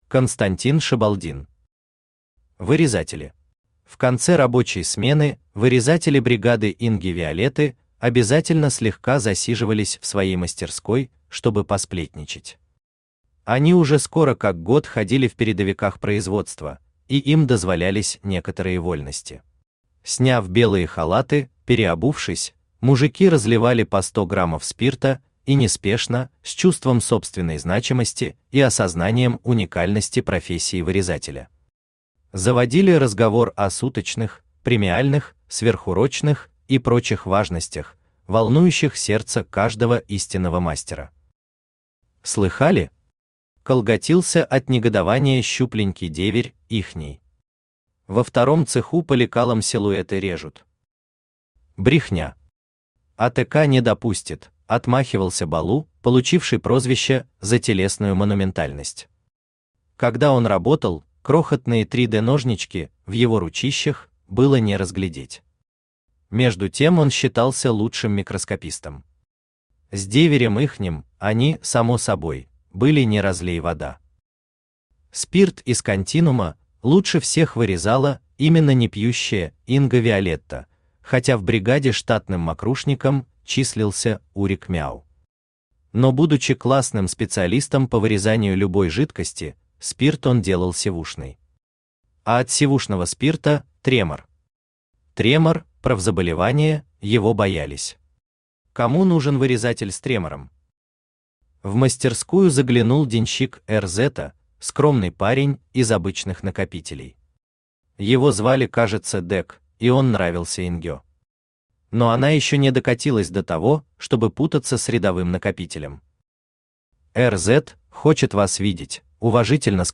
Aудиокнига Вырезатели Автор Константин Шабалдин Читает аудиокнигу Авточтец ЛитРес.